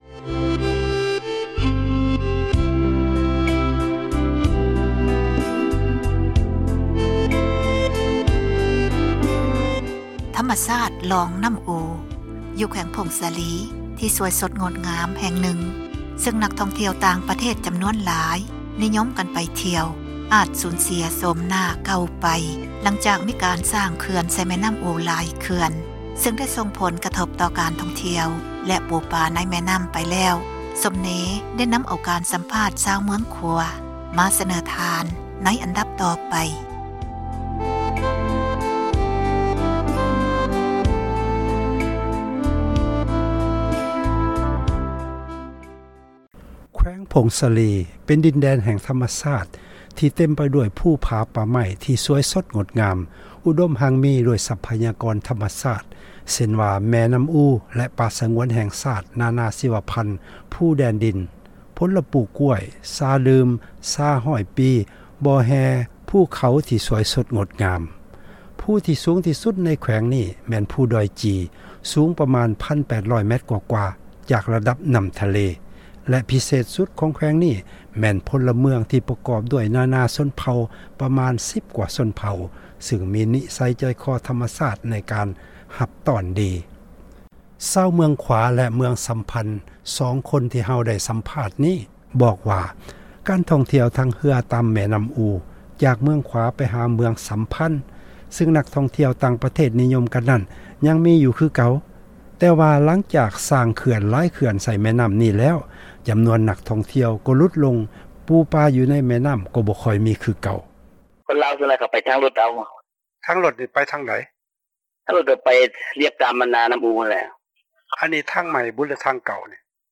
ຊາວເມືອງຂວາແລະສັມພັນ ສອງຄົນ ທີ່ເຮົາໄດ້ສັມພາດນີ້ ບອກວ່າ ການທ່ອງທ່ຽວ ທາງເຮືອ ຕາມແມ່ນໍ້າອູ ຈາກເມືອງຂວາ ໄປຫາເມືອງ ສັມພັນ ຊຶ່ງນັກທ່ອງທ່ຽວ ຕ່າງປະເທດ ນິຍົມກັນນັ້ນ ຍັງມີຢູ່ຄືເກົ່າ, ແຕ່ວ່າຫລັງຈາກ ສ້າງເຂື່ອນ ຫຼາຍເຂື່ອນໃສ່ນໍ້າ ສາຍນີ້ແລ້ວ ຈໍານວນ ນັກທ່ອງທ່ຽວ ກໍຫລຸດລົງ ປູປາໃນນໍ້າ ກໍບໍຄ່ອຍມີ ຄືເກົ່າ.